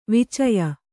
♪ vicaya